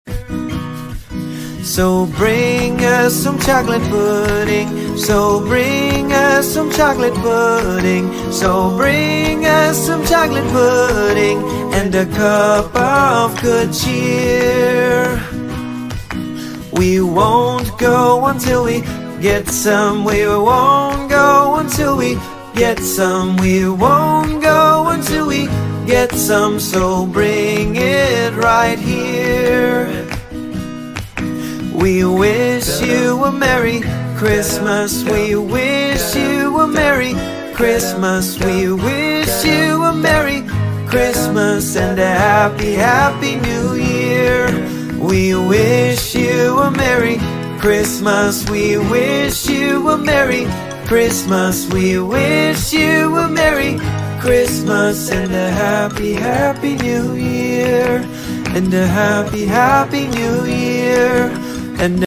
Jingle Bells ｜ Christmas Songs sound effects free download
Nursery Rhymes